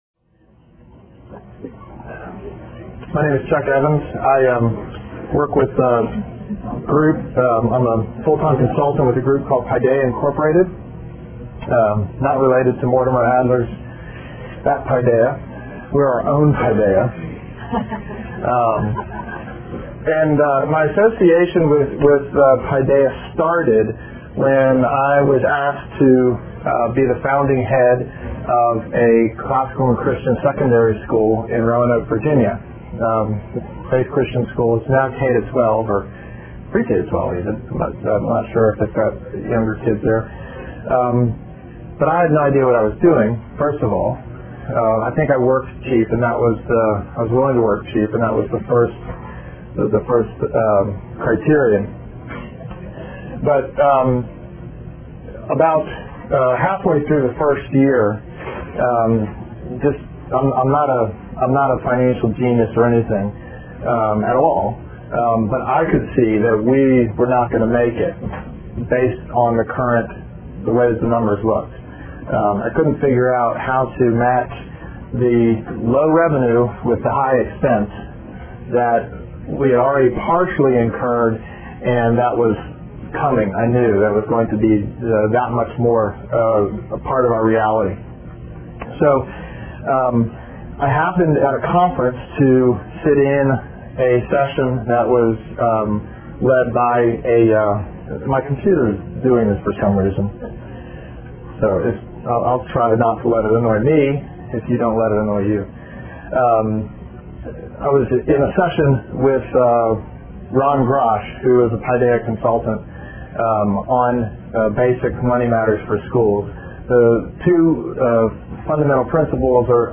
This seminar introduces a framework for financial sustainability, and begins to answer these questions: What does quality education really cost?